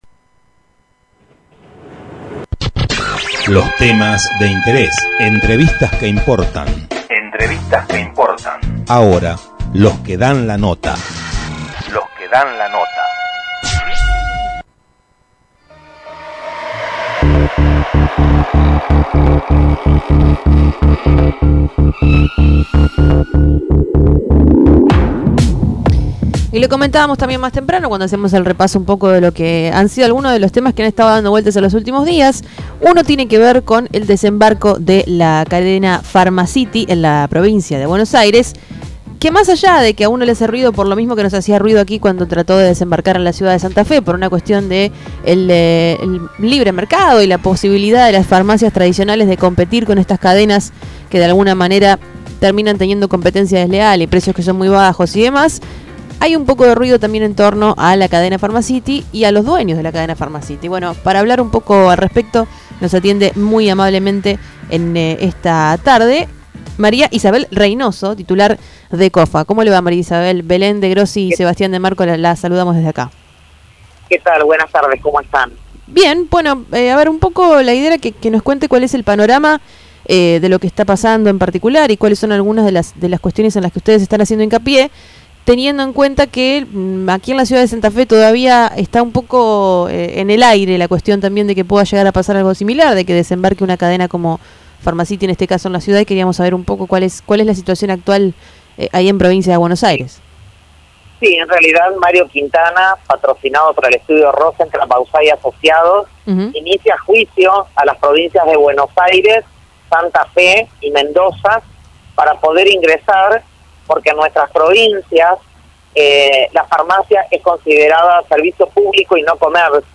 Radio comunitaria 100.9